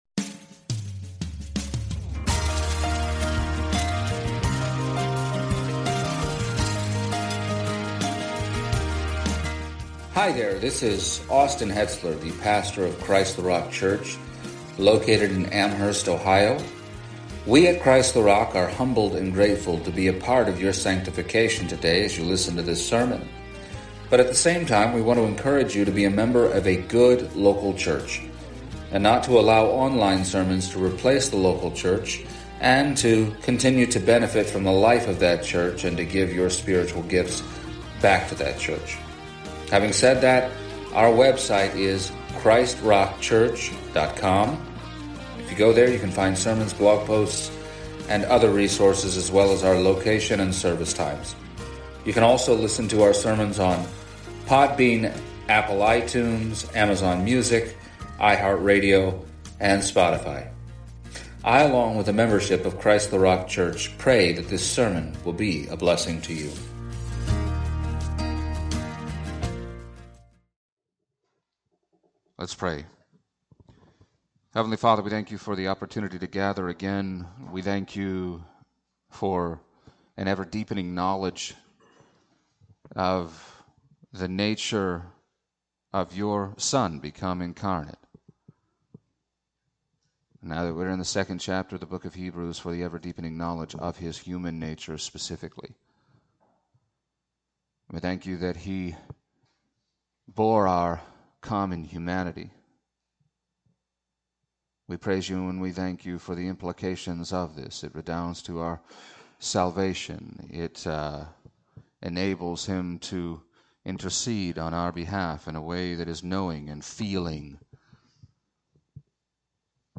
Hebrews 2:10-13 Service Type: Sunday Morning To what extent is Jesus our elder brother?